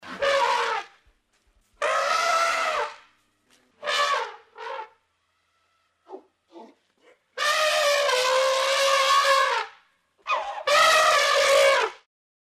На этой странице собраны разнообразные звуки морского слона – от мощного рева самцов до нежных голосов детенышей.
Крик слона